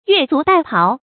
注音：ㄩㄝˋ ㄗㄨˇ ㄉㄞˋ ㄆㄠˊ
讀音讀法：
越俎代庖的讀法